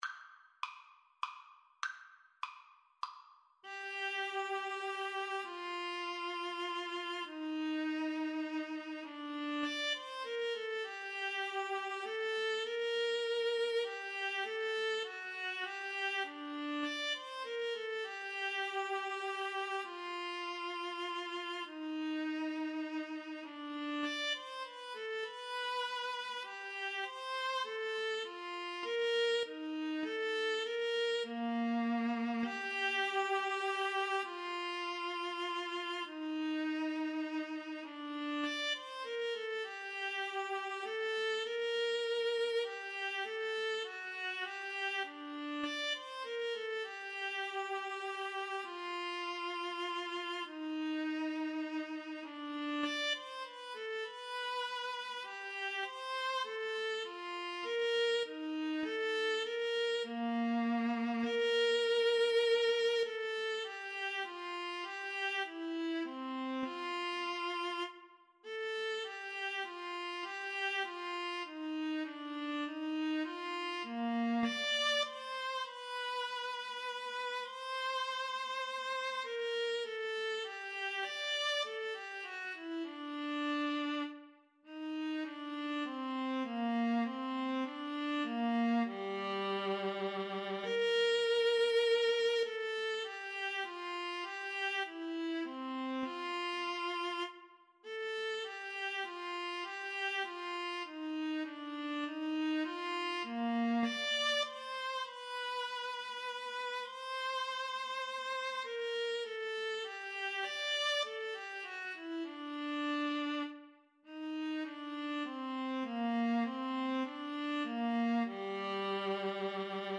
3/4 (View more 3/4 Music)
Classical (View more Classical Violin-Viola Duet Music)